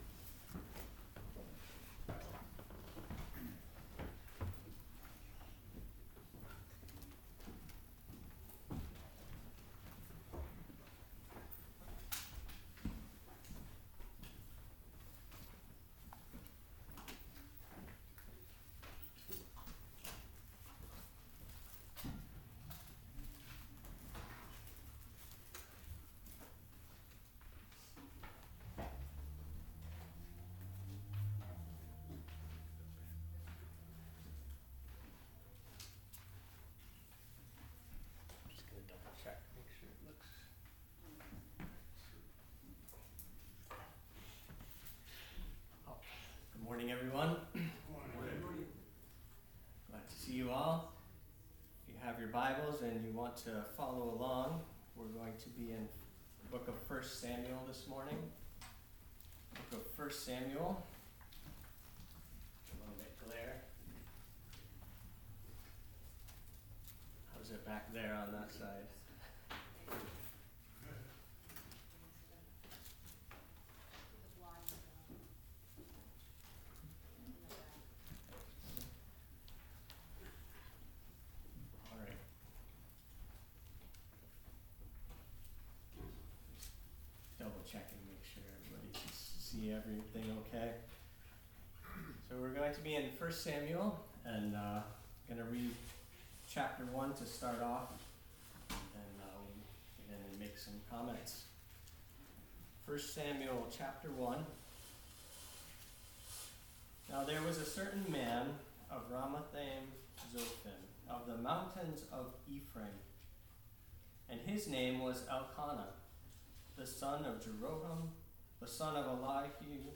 Religion and Relationship Passage: 1 Samuel 1 Service Type: Sunday Afternoon « 11.05.23